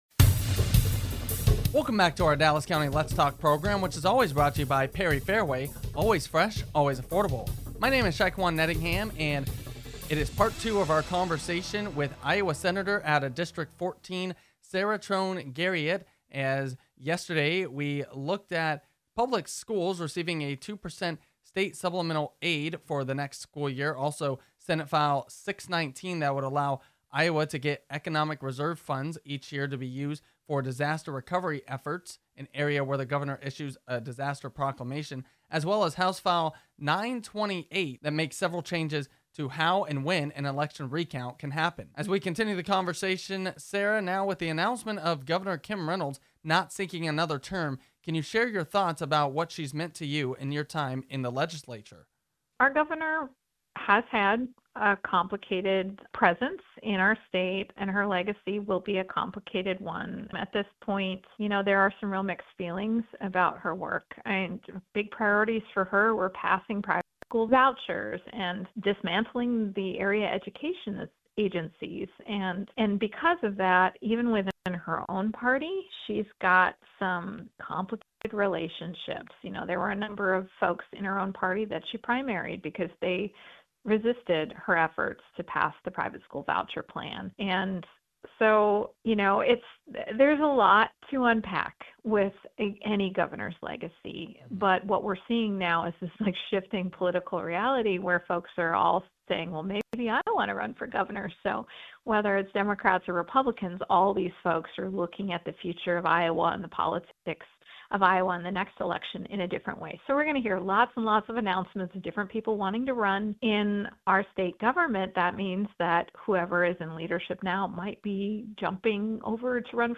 Today’s Let’s Talk Dallas County program features Iowa District 14 Senator Sarah Trone-Garriott for part two of our conversation as she discusses Governor Kim Reynolds’ retirement, her running for state office, and more.